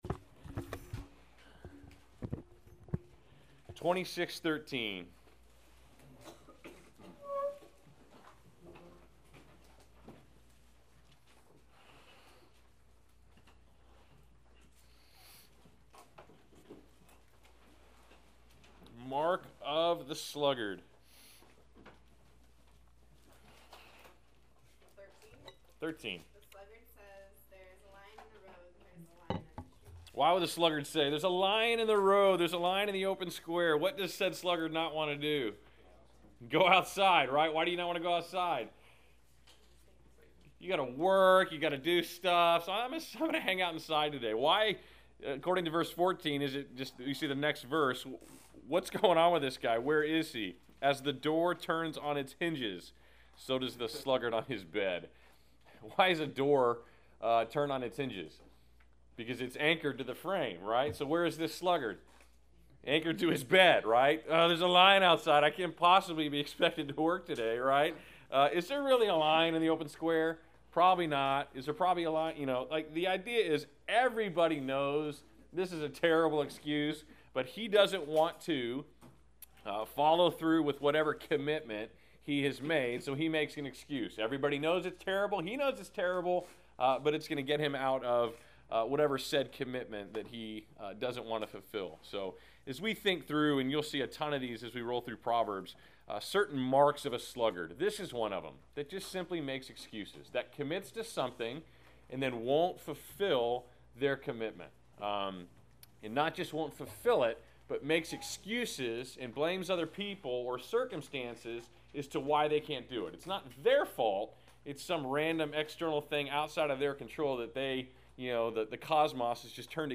Class Session Audio October 16